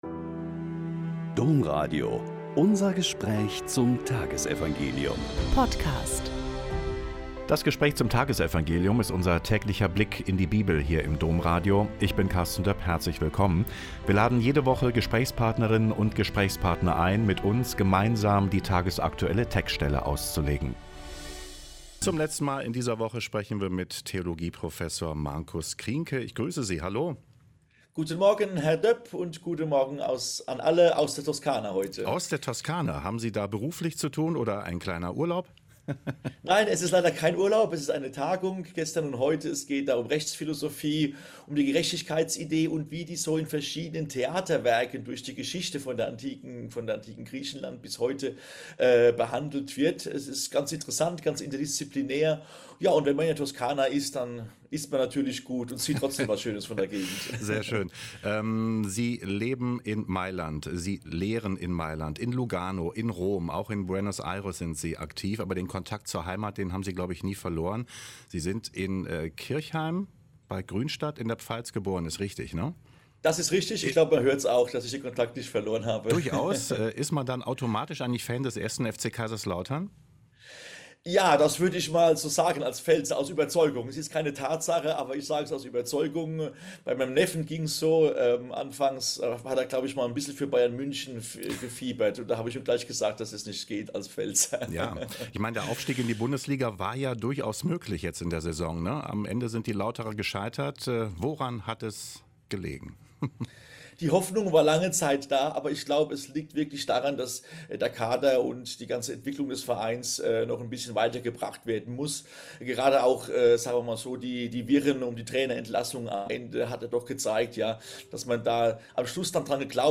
Joh 15,18-21 - Gespräch